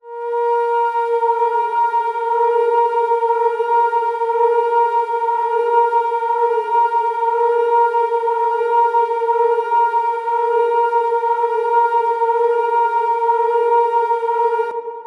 Category: Scary Ringtones